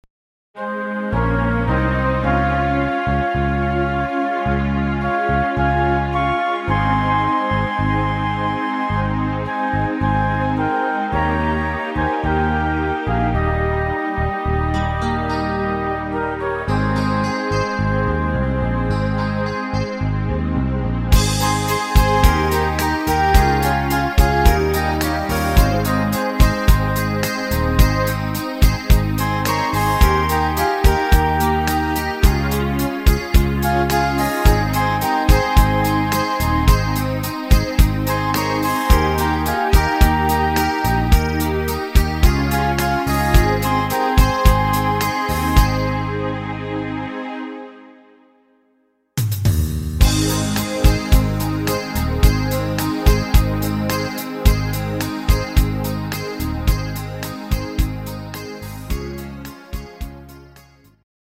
Rhythmus  Slow Beat
Art  Pop, Englisch, Oldies